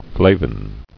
[fla·vin]